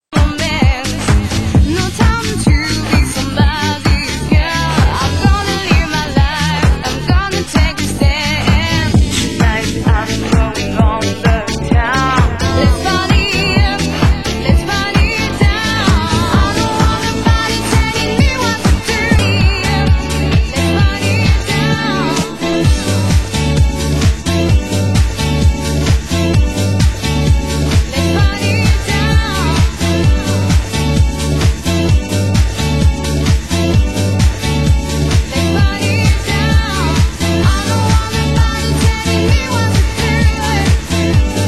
Genre: UK House
ext. club vocal